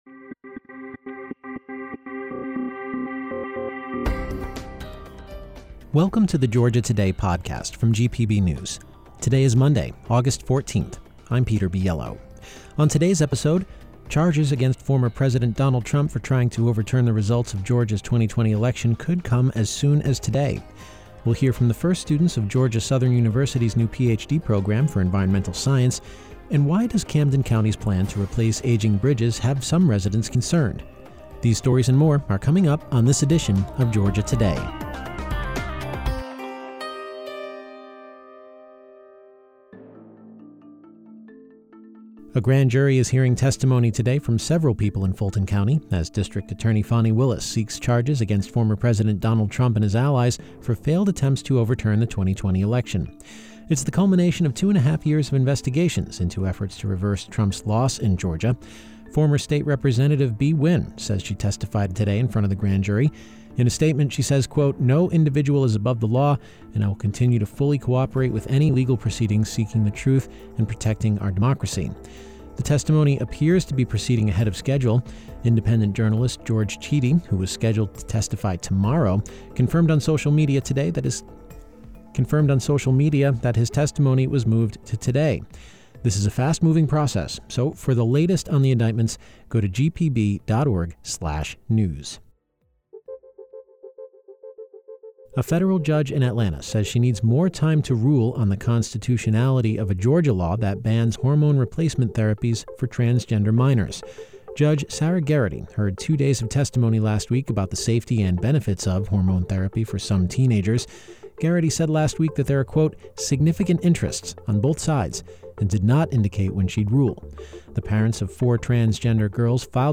Beschreibung vor 2 Jahren LISTEN: On the Monday, Aug. 14 edition of Georgia Today: Charges for former president Donald Trump for trying to overturn the results of Georgia's 2020 election could come as soon as today; we hear from the first students of Georgia Southern University's new Ph.D. program for environmental science; and why does Camden County's plan to replace aging bridges have some residents concerned?